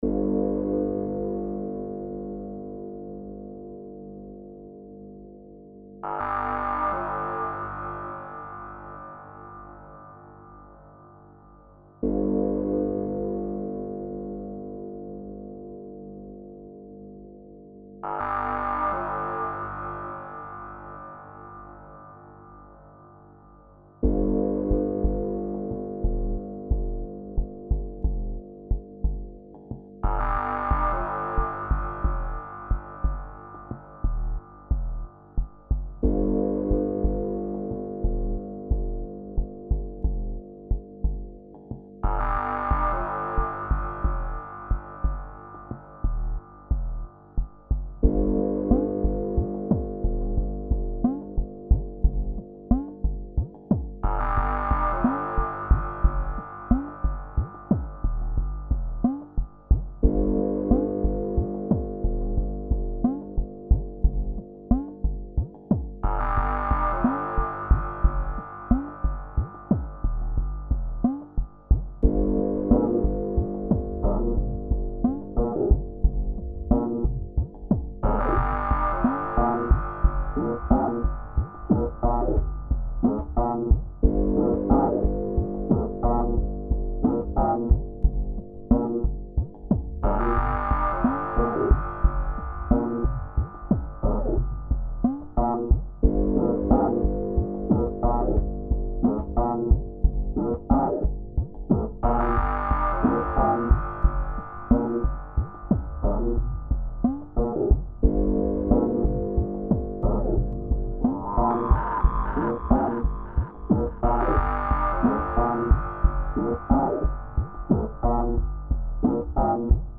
It's got some crazy strings in it.
Experimental